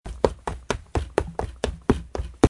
Download Running sound effect for free.
Running